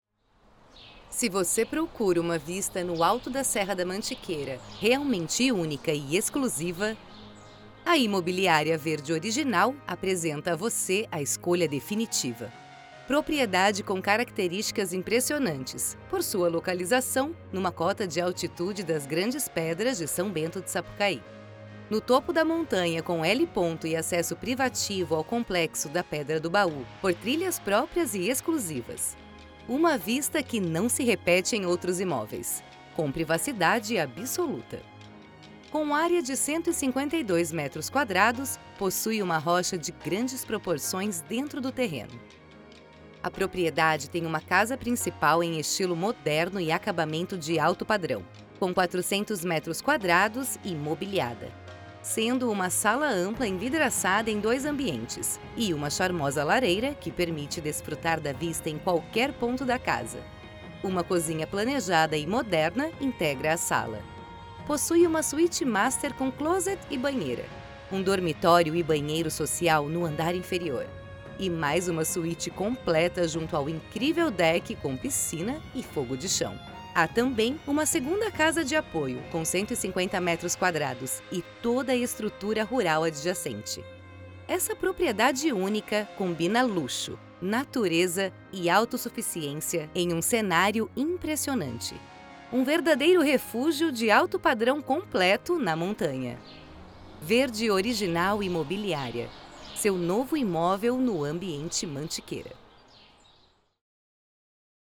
With a versatile, mature, and pleasant voice, I am able to adapt my narration style to the needs of the project, creating a unique and engaging experience for the listener.
I have a professional Home Studio equipped with Neumann 107, Mac, SSL2 Interface, AKG Headphones, soundproofed room and source connect for remote recordings.